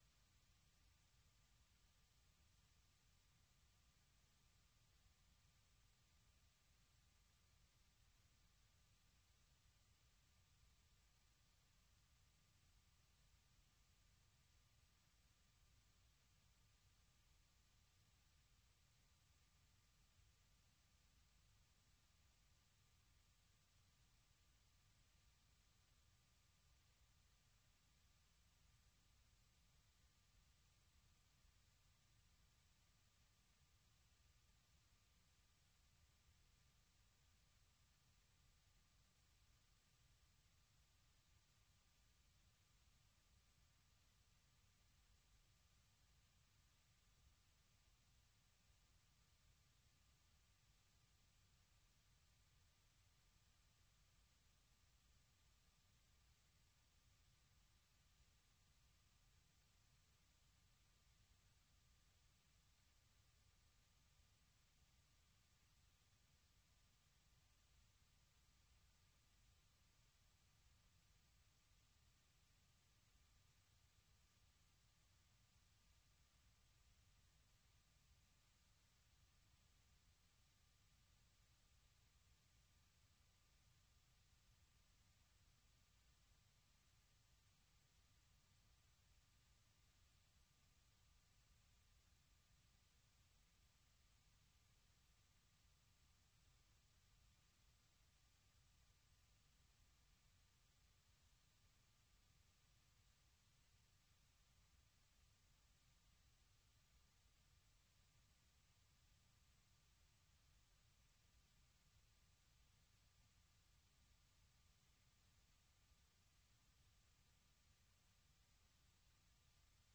propose notamment d'écouter de la musique africaine